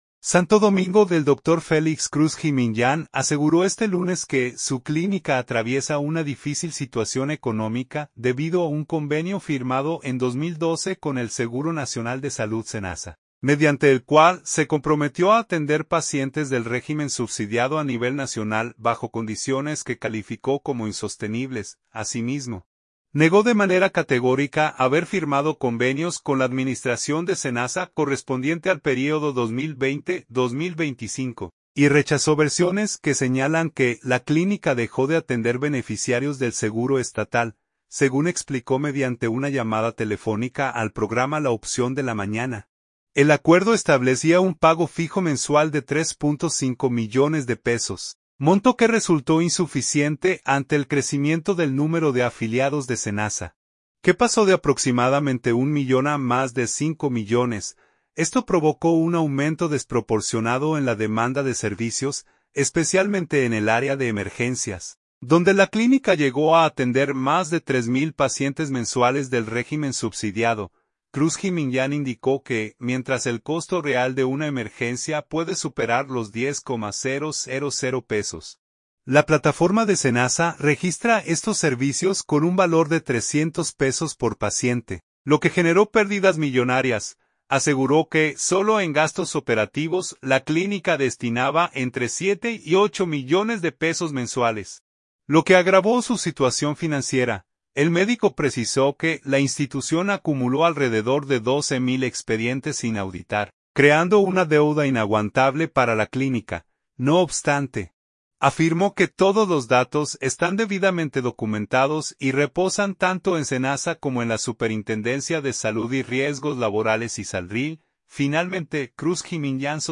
Según explicó mediante una llamada telefónica al programa "La Opción de la Mañana", el acuerdo establecía un pago fijo mensual de 3.5 millones de pesos, monto que resultó insuficiente ante el crecimiento del número de afiliados de SENASA, que pasó de aproximadamente un millón a más de cinco millones.